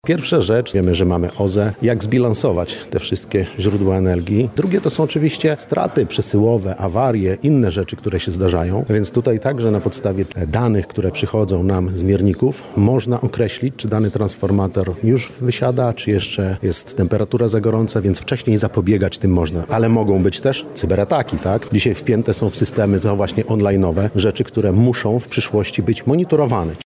Konferencja ekspercka odbywa się w Centrum EcoTech Uniwersytetu Marii Curie-Skłodowskiej w Lublinie.